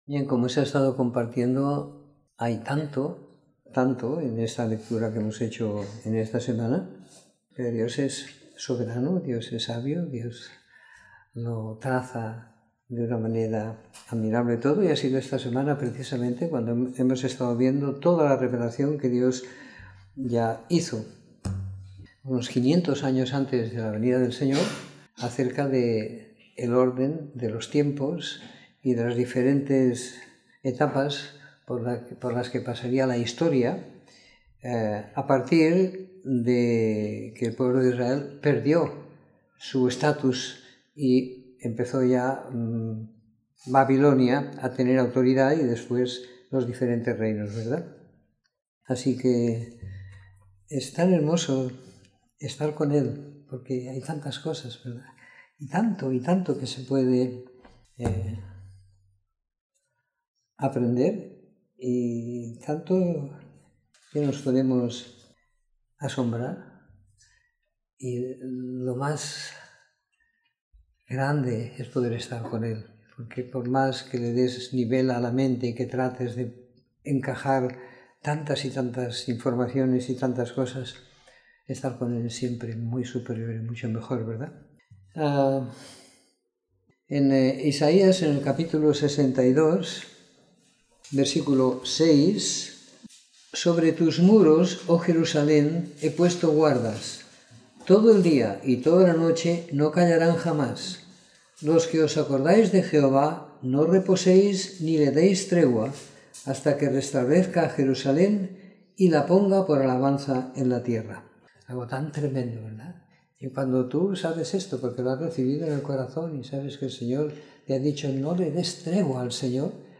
Comentario en los libros de Daniel y Oseas siguiendo la lectura programada para cada semana del año que tenemos en la congregación en Sant Pere de Ribes.